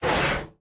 foundry-slide-open.ogg